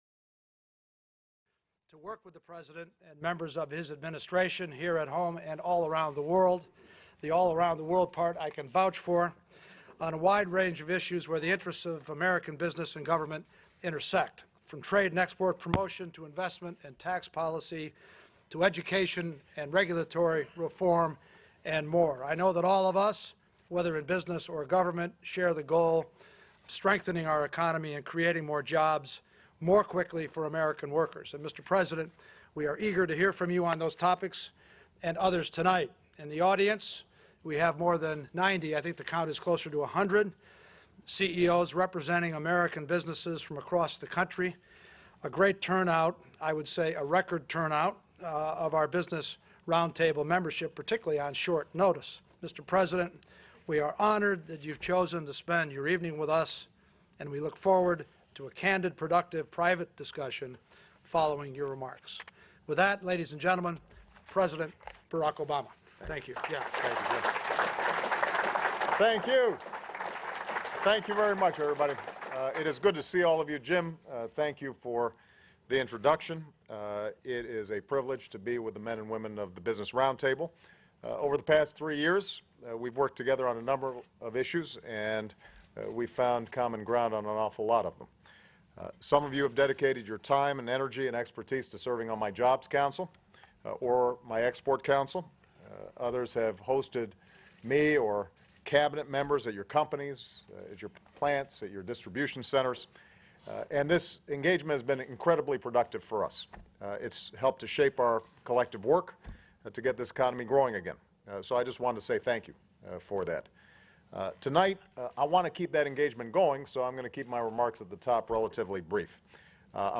U.S. President Barack Obama speaks to members of the Business Roundtable